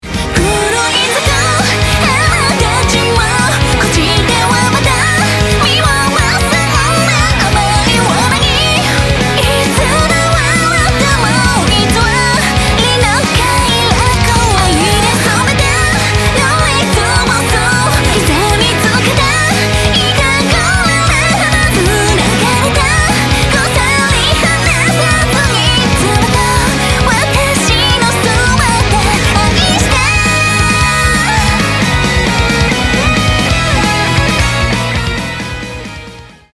aesthetic fantasy symphonia